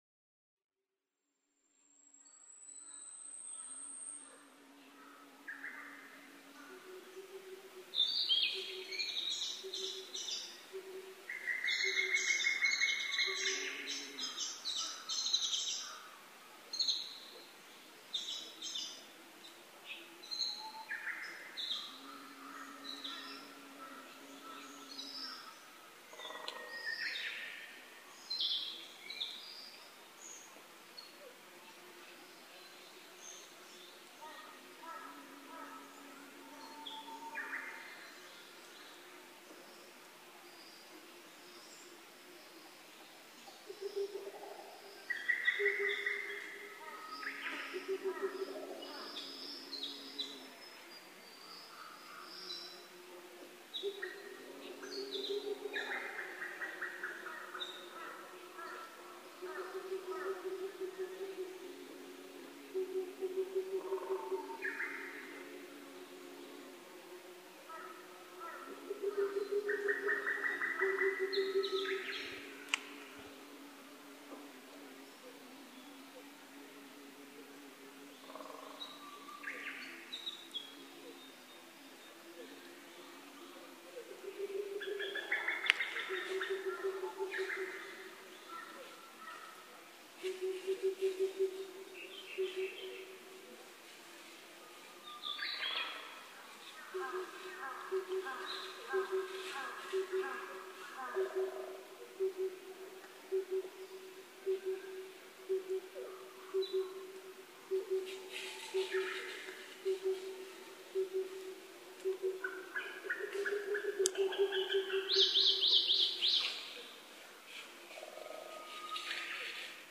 ツツドリ　Cuculus saturatusカッコウ科
日光市土呂部　alt=940m
Mic: Panasonic WM-61A  Binaural Souce with Dummy Head
ツツドリどおしが二羽激しく鳴き合います。
他の自然音：ハシブトガラス、ウグイス、足音、キビタキ、ヤブサメ、モリアオガエル、センダイムシクイ